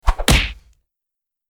Tiếng Cú Đấm bịch (nhanh)
Tiếng Bốp (cú đấm) Tiếng Cú Đấm và tiếng ừc….
Thể loại: Đánh nhau, vũ khí
tieng-cu-dam-bich-nhanh-www_tiengdong_com.mp3